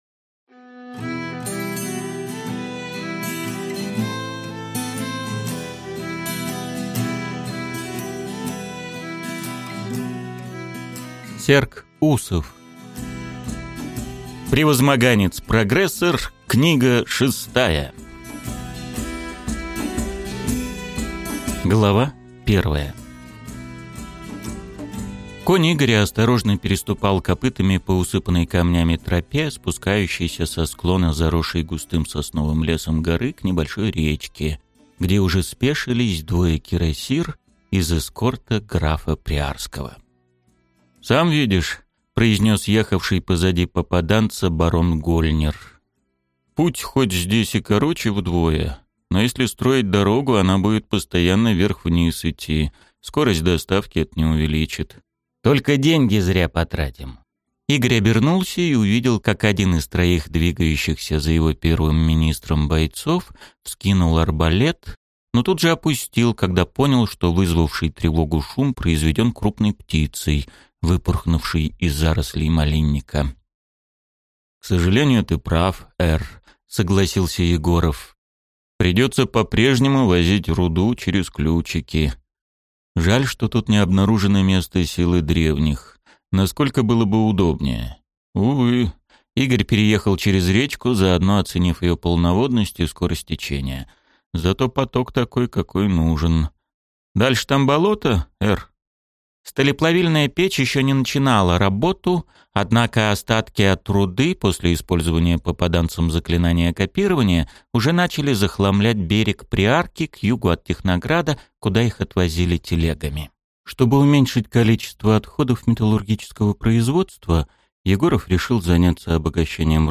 Аудиокнига Превозмоганец-прогрессор. Книга 6 | Библиотека аудиокниг